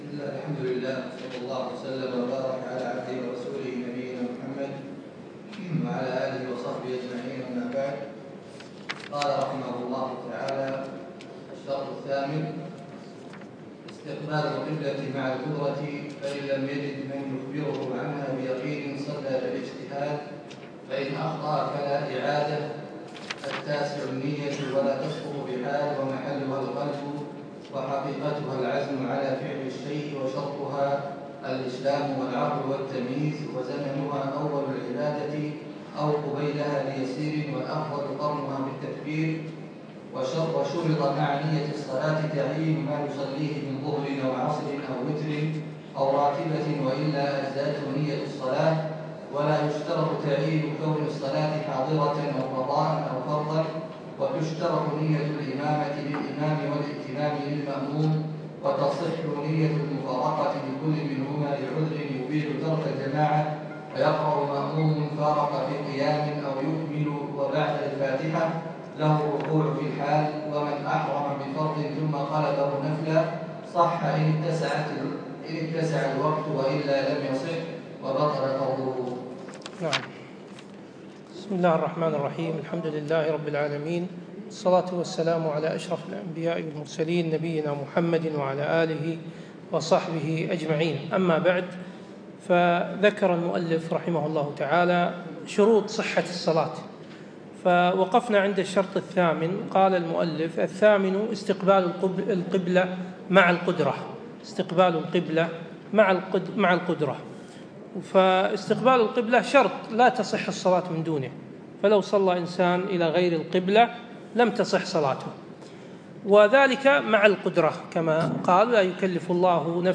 الدرس الثاني: من الشرط الثامن من شروط الصلاة إلى نهاية السنن القولية